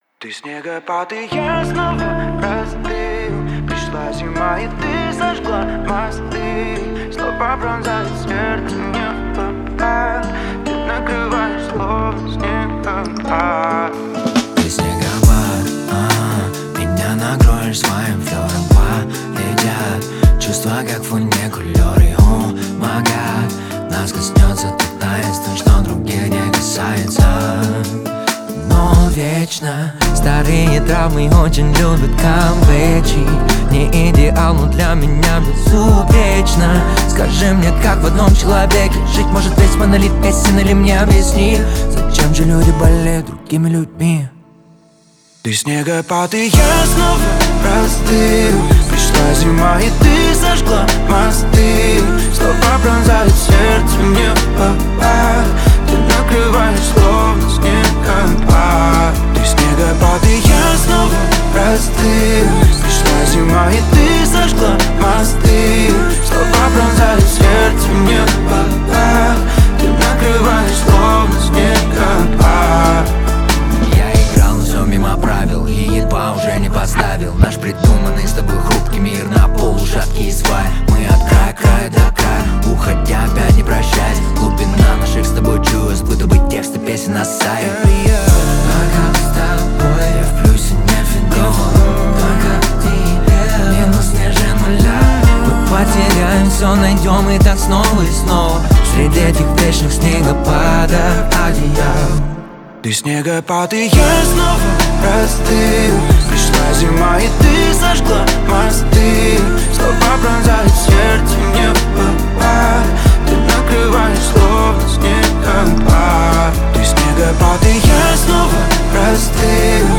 Русские песни
лиричные песни